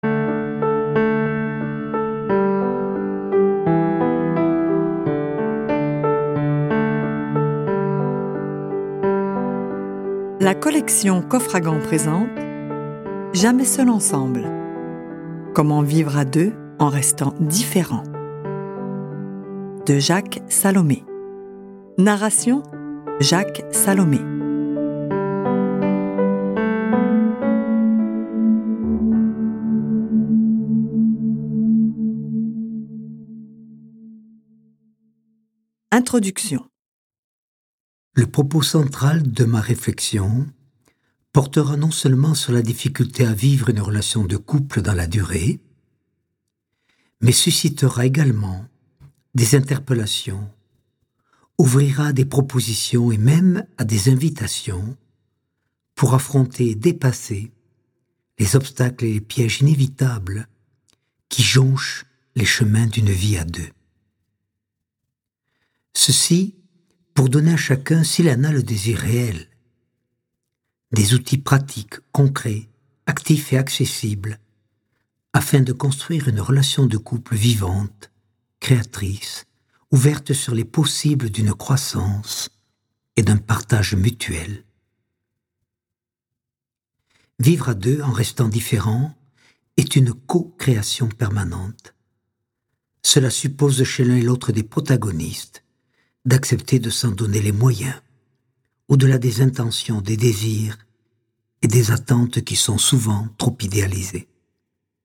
Lu par Jacques Salomé Durée : 02h01min 20 , 00 € Ce livre est accessible aux handicaps Voir les informations d'accessibilité